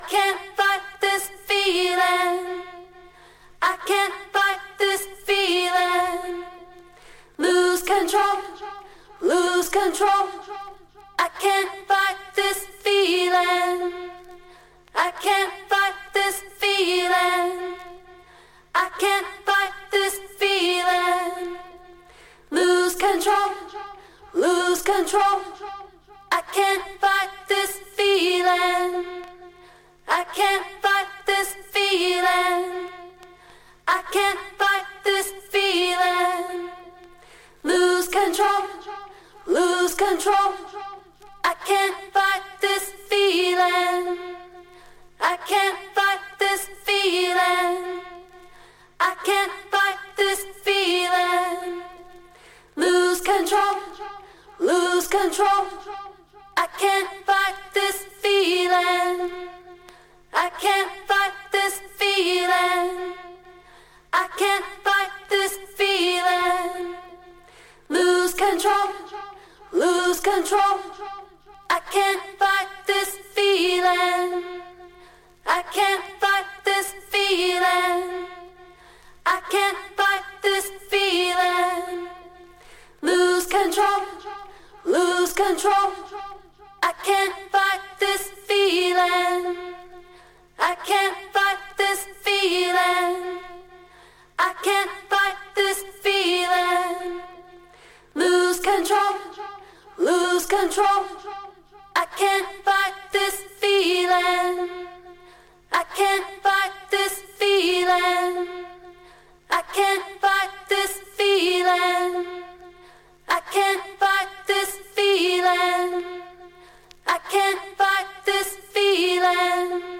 acid track
punkish and eccentric singing voice